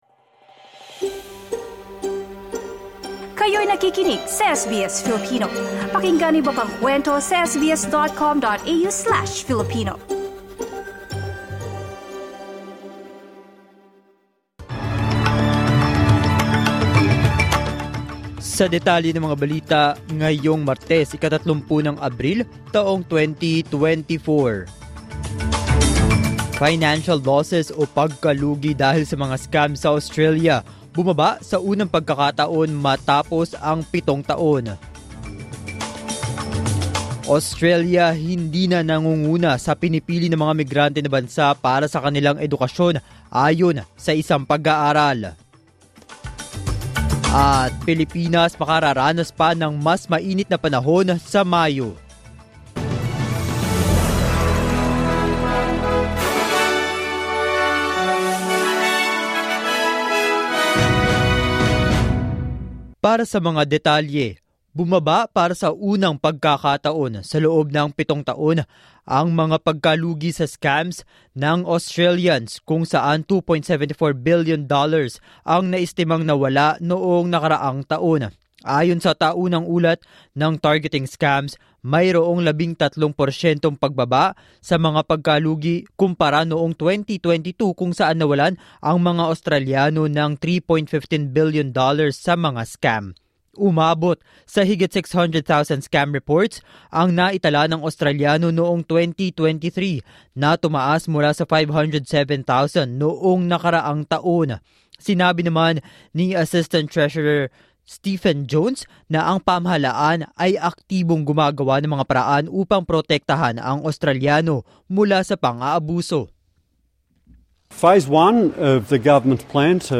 SBS News in Filipino, Tuesday 30 April 2024